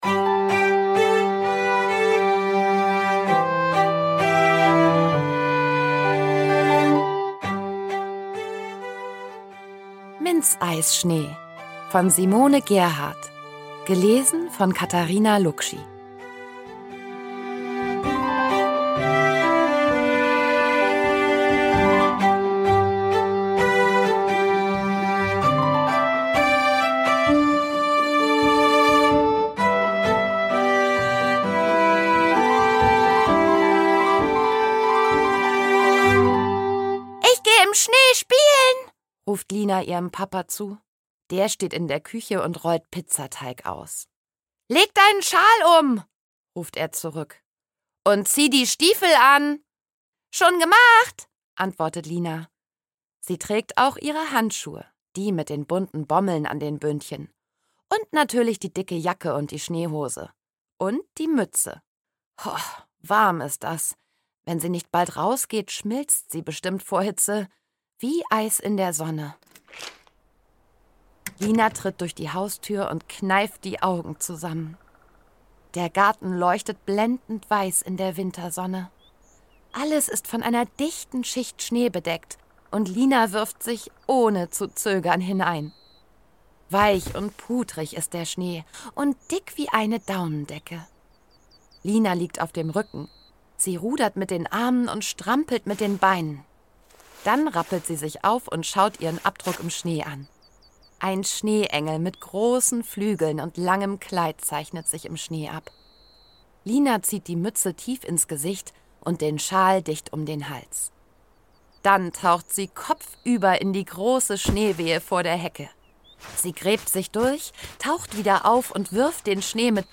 Vorlesebücher und Hörbuch-Geschichten
Für Tiger-Media habe ich zwei Geschichten geschrieben, die eingelesen und in den tigertones Hör-Adventskalendern veröffentlicht wurden.